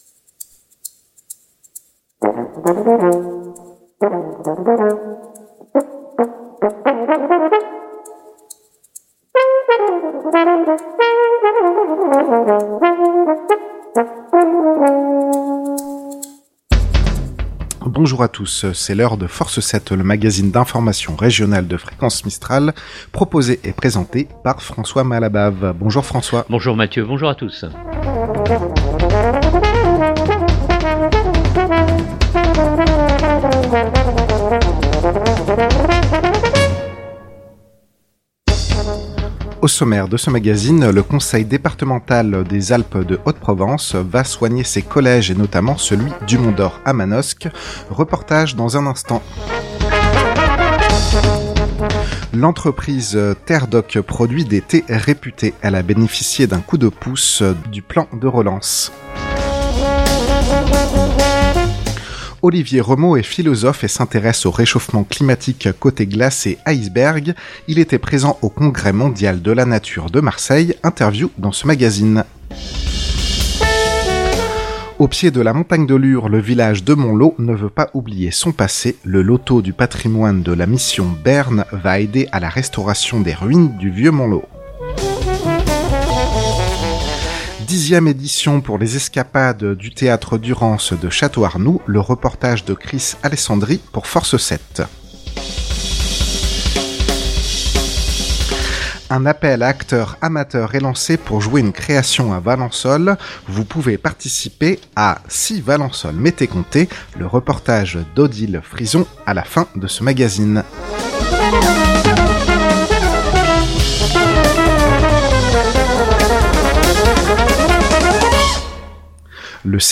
Interview dans ce magazine.